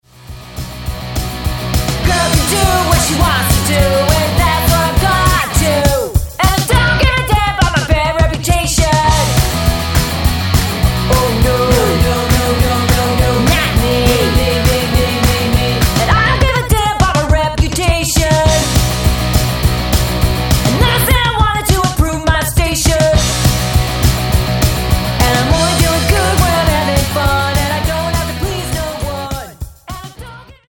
--> MP3 Demo abspielen...
Tonart:B-C# Multifile (kein Sofortdownload.